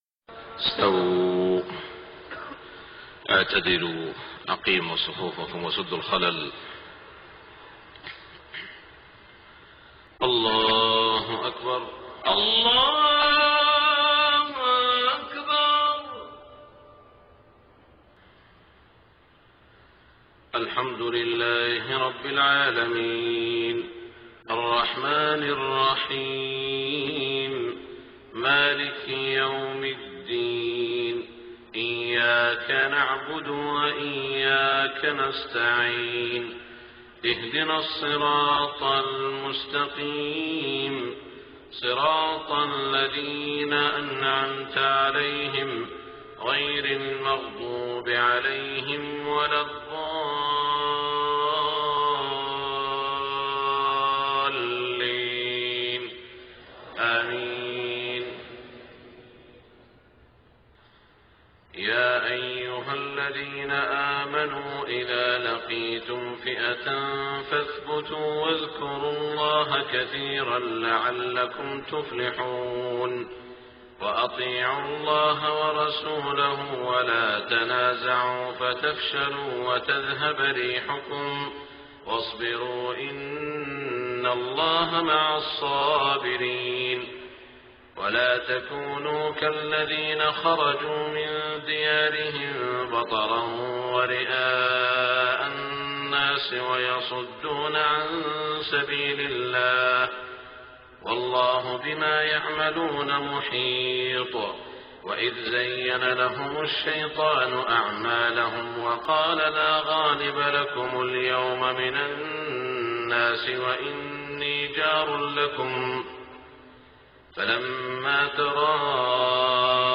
صلاة الفجر 2-7-1426 من سورة الأنفال > 1426 🕋 > الفروض - تلاوات الحرمين